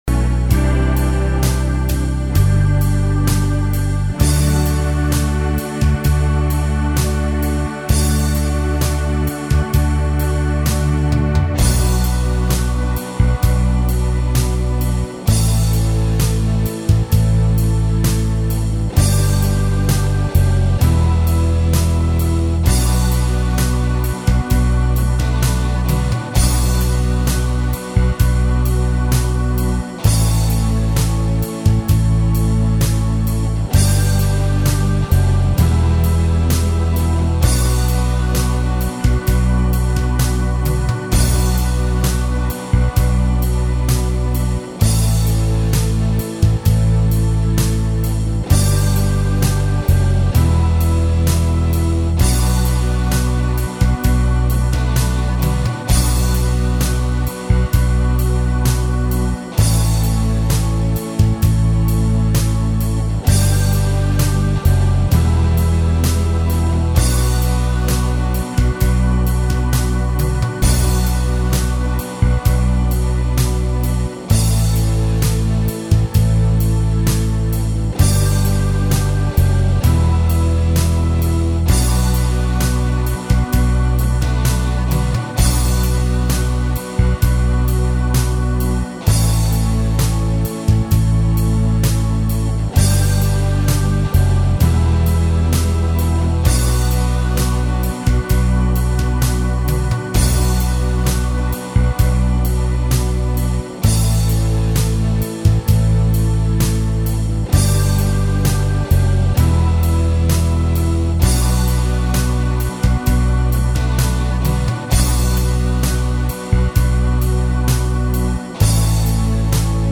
SOLO2 EXTENDED